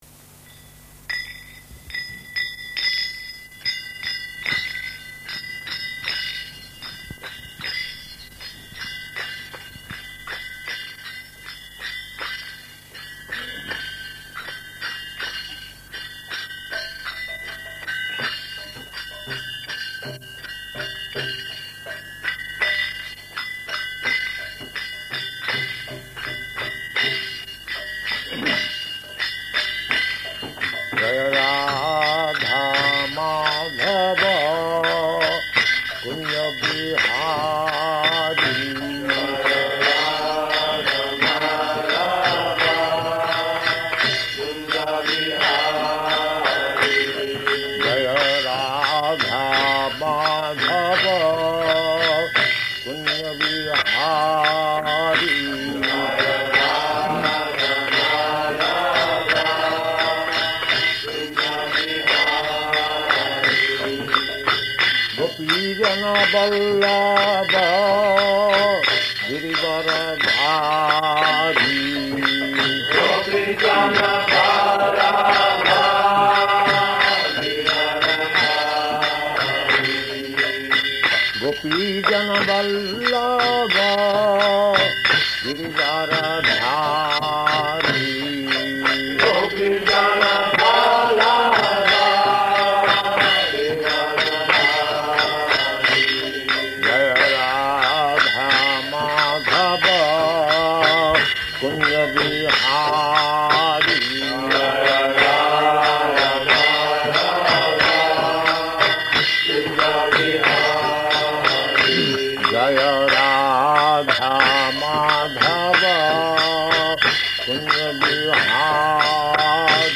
Śrīmad-Bhāgavatam 1.1.10 --:-- --:-- Type: Srimad-Bhagavatam Dated: July 20th 1972 Location: London Audio file: 720720SB.LON.mp3 Prabhupāda: [leads singing of Jaya Rādhā-Mādhava ] [ prema-dhvani ] Thank you very much.
[leads chanting of verse] [Prabhupāda and devotees repeat] prāyeṇālpāyuṣaḥ sabhya kalāv asmin yuge janāḥ mandāḥ sumanda-matayo manda-bhāgyā hy upadrutāḥ [ SB 1.1.10 ] Prabhupāda: Next?